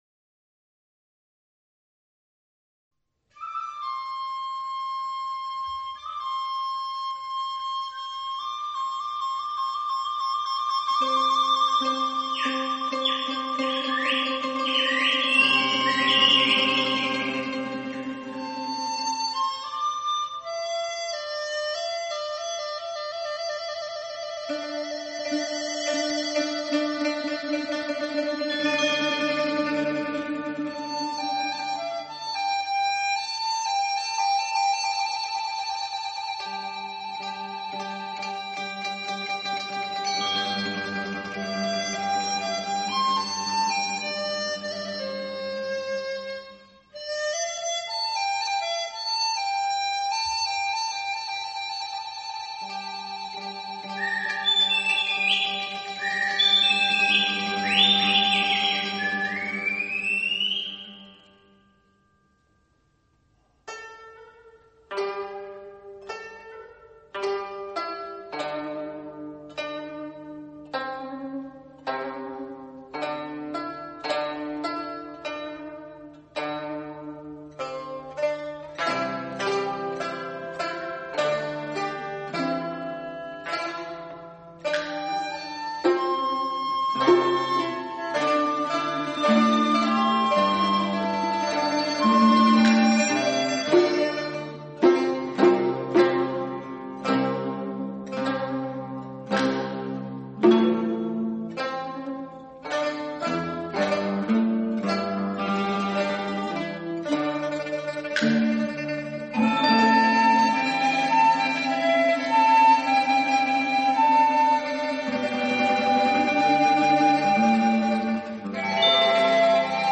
合奏